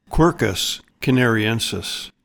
Pronounciation:
QUER-cus ca-nar-ee-EN-sis